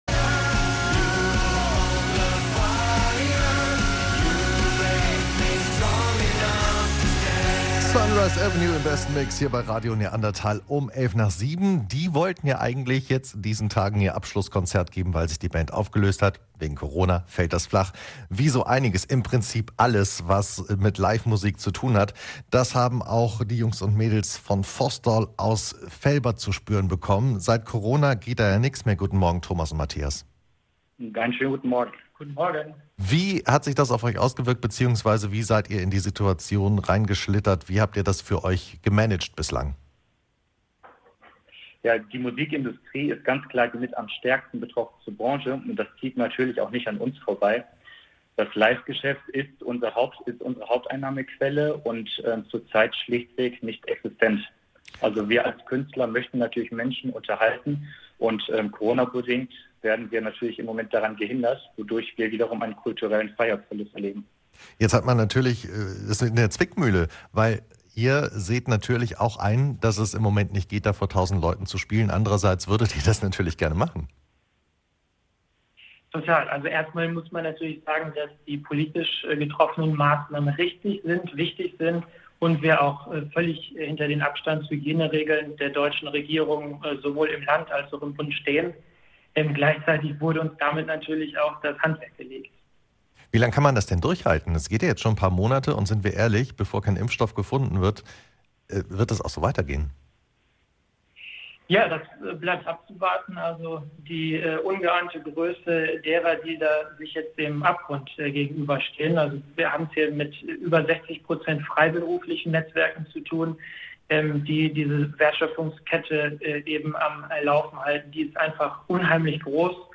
Kunst und Kultur, das sind die Branchen, die im Moment in Coronazeiten besonders leiden. Wir haben mit der Band Foss Doll aus Velbert gesprochen.